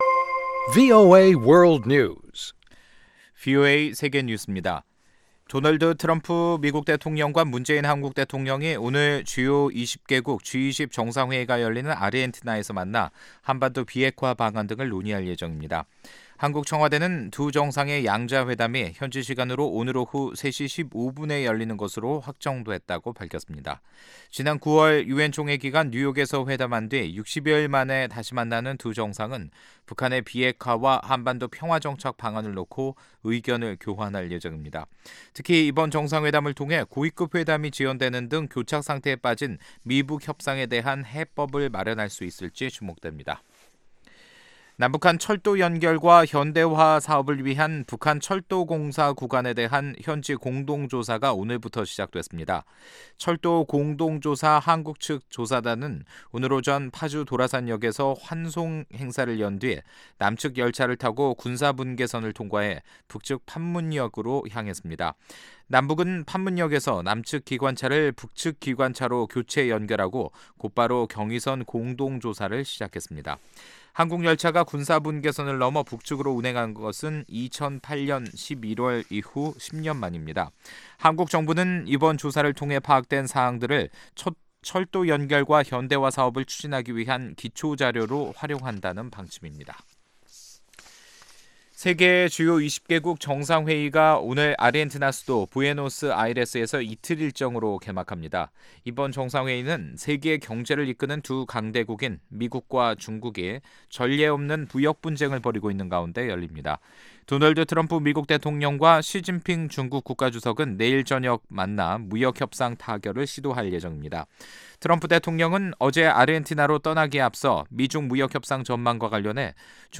VOA 한국어 간판 뉴스 프로그램 '뉴스 투데이', 2018년 11월 30일 3부 방송입니다. 미국 국무부가 북한과의 고위급 회담 기대를 밝히면서 스티븐 비건 대북정책 특별대표가 대화를 이끌 것이라고 강조했습니다. 미국은 해외에서 벌어지는 북한의 제재 회피활동과 수단을 막기 위해 거의 매일 관련사안을 논의하고 있다고 재무부가 밝혔습니다.